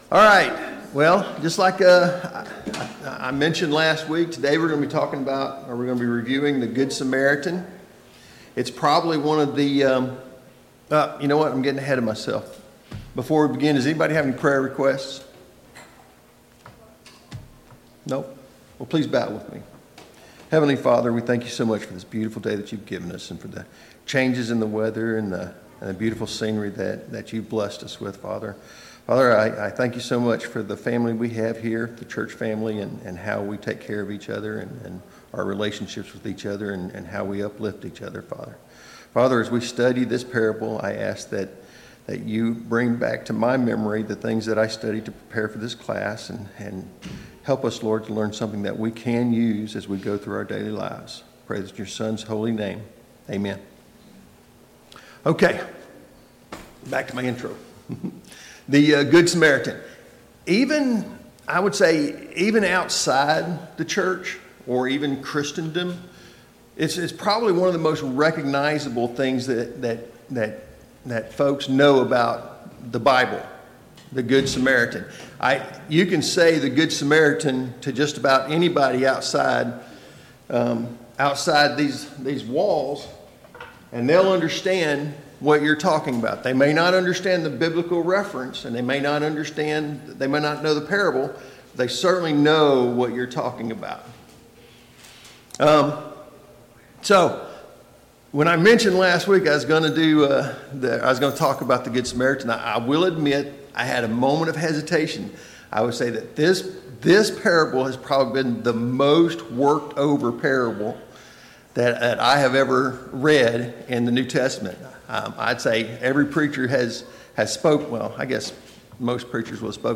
Sunday Morning Bible Class Topics: The Good Samaritan « 16.